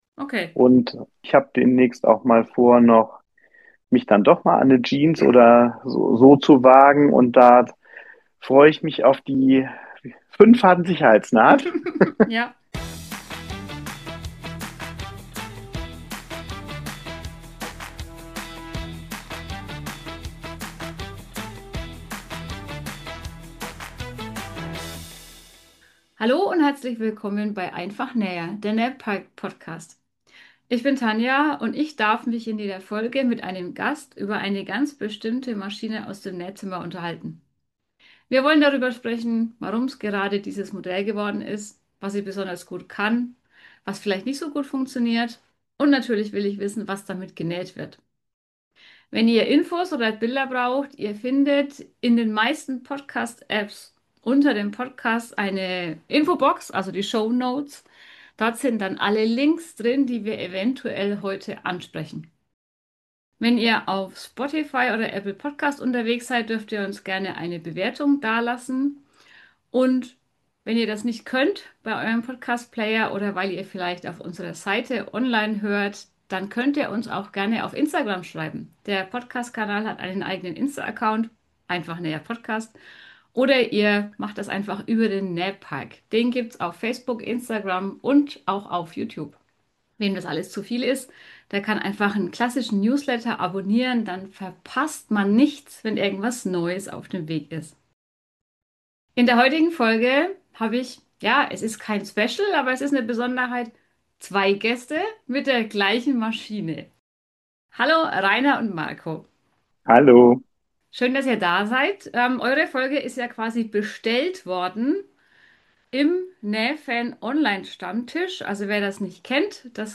Zwei Männer und zwei Berninas - und alle sind jetzt glücklich!